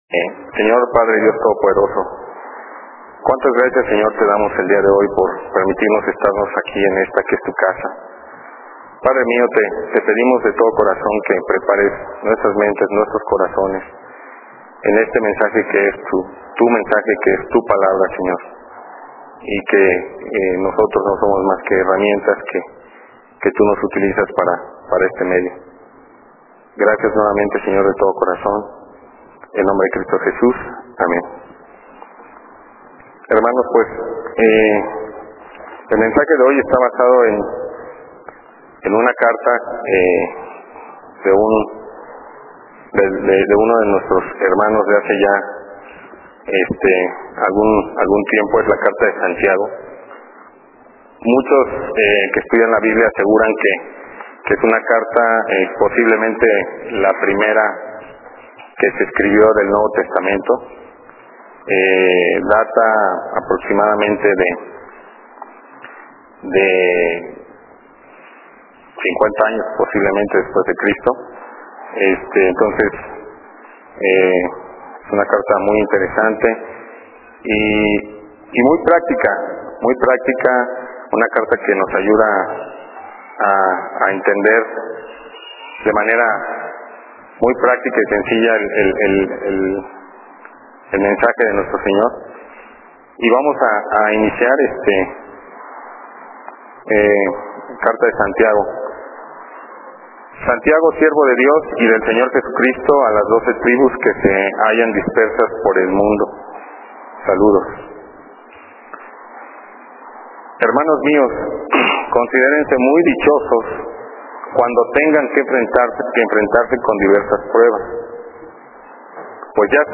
Sermones de Santiago